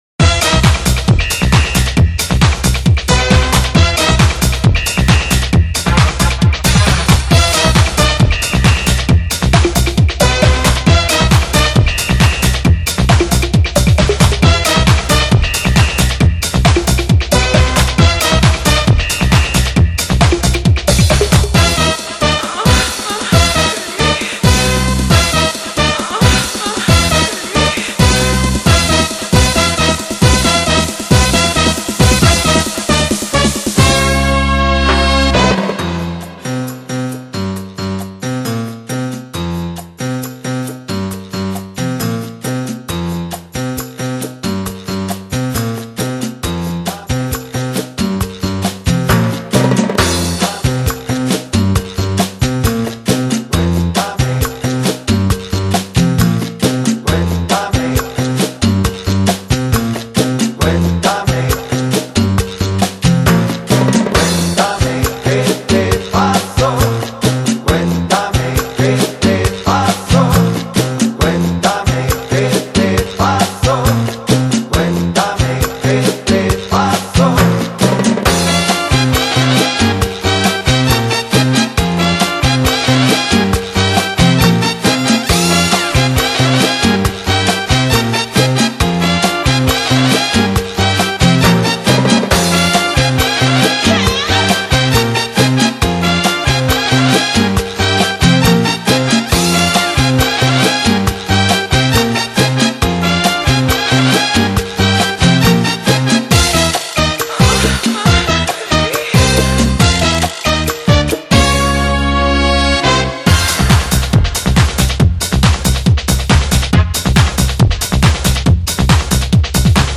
录DANCE），是一张具有曼波神韵的音乐专辑。
门金曲，旋律性强而富有动感，散发着无穷的时尚气息。